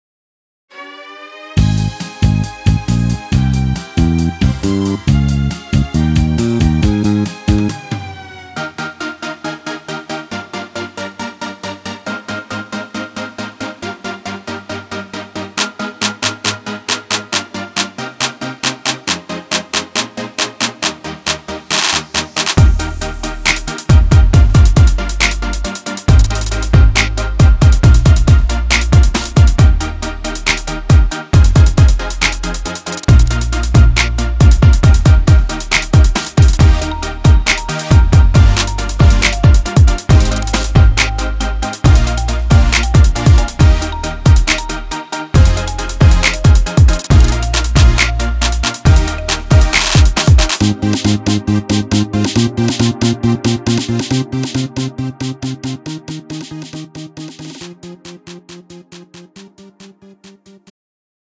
음정 원키 3:30
장르 가요 구분 Pro MR